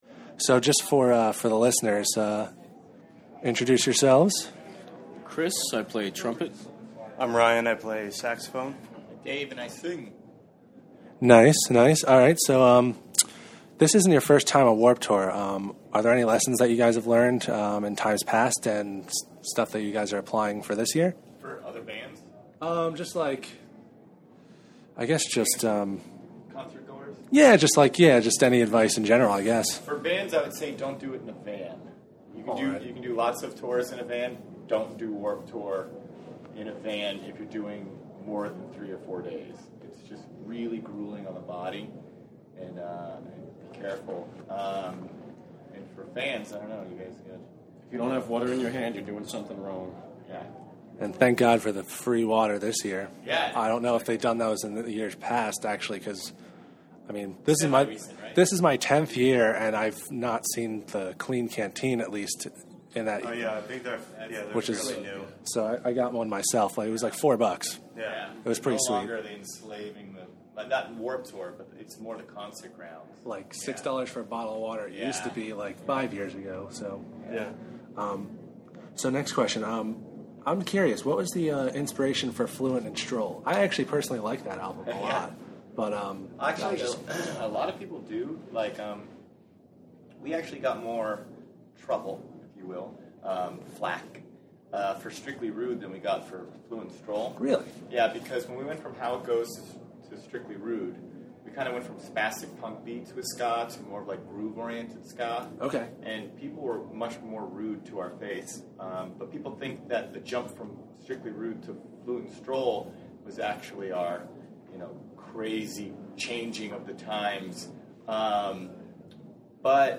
Exclusive: Big D and the Kids Table Interview
07-interview-bid-d-and-the-kids-table.mp3